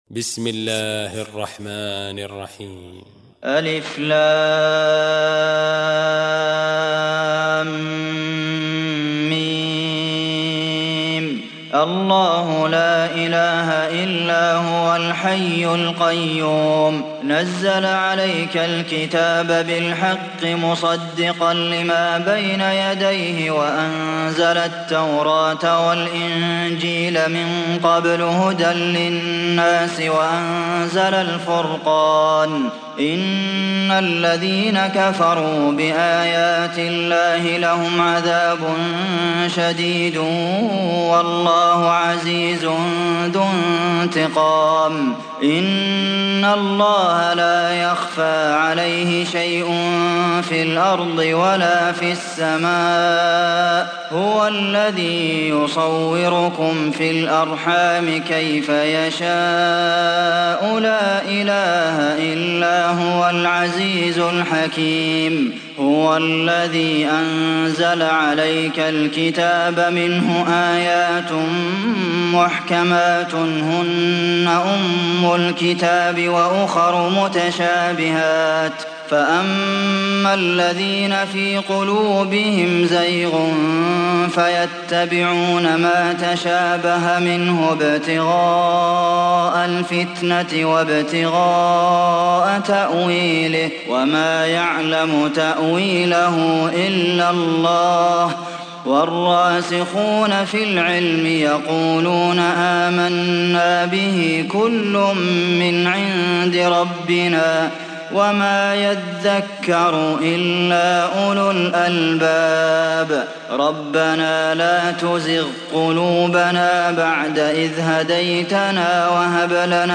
Sourate Al Imran Télécharger mp3 Abdulmohsen Al Qasim Riwayat Hafs an Assim, Téléchargez le Coran et écoutez les liens directs complets mp3